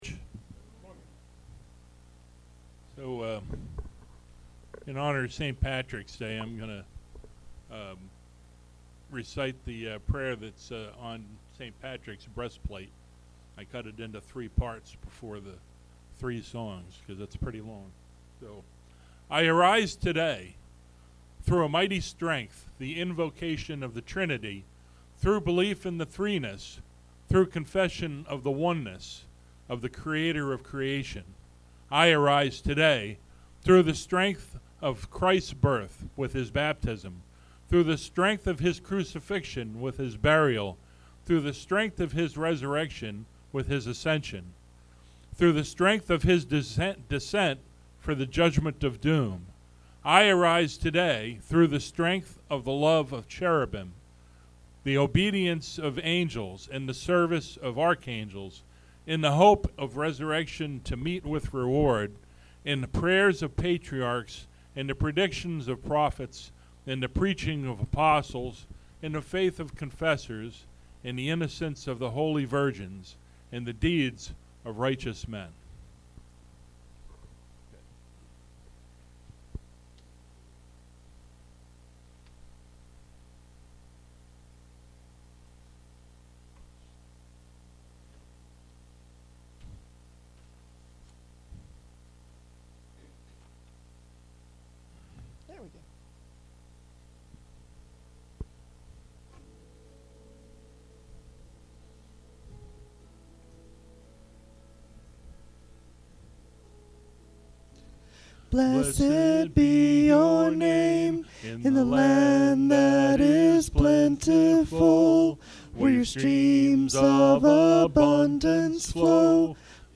Podcast (sermons): Play in new window | Download